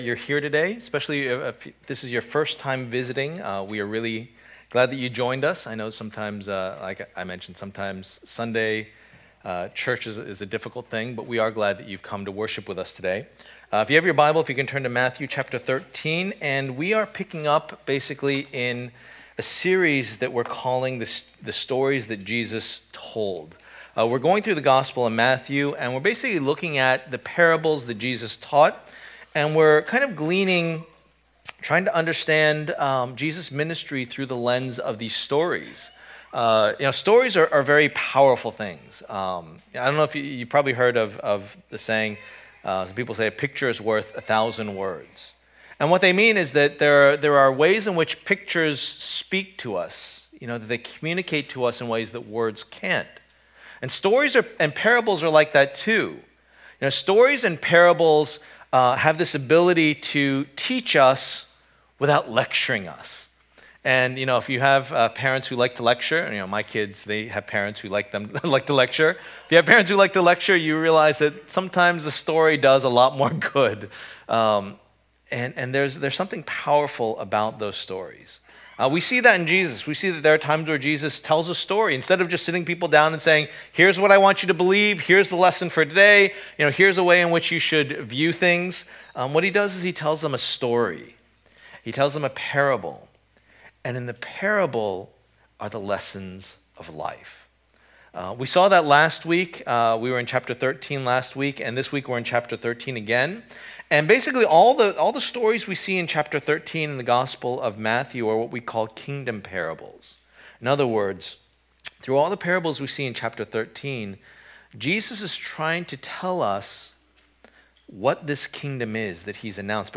2019 The Two Kingdoms Preacher